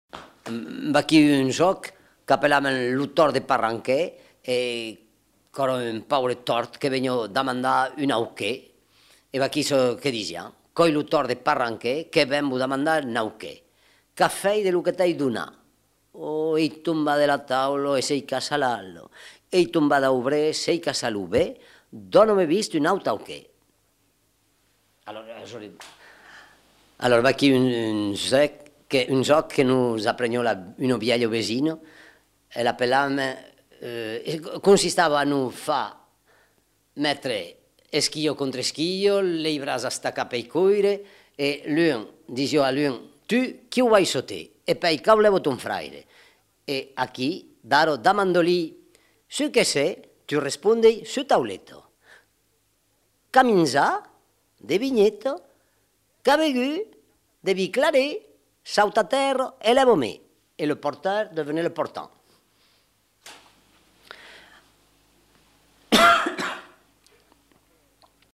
Aire culturelle : Haut-Agenais
Genre : forme brève
Type de voix : voix d'homme
Production du son : récité
Classification : formulette enfantine